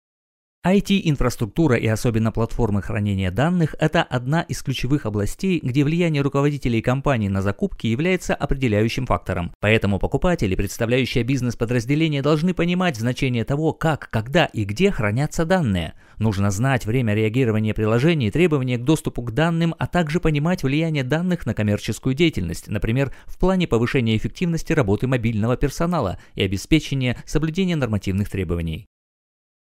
Friendly, plastic and artistic voice.
Sprechprobe: Werbung (Muttersprache):